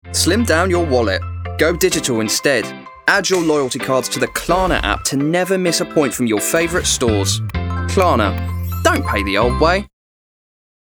• Male
Commercial - Klarna. Upbeat, Informative
2.-Buy-now-Pay-Later-Commercial.mp3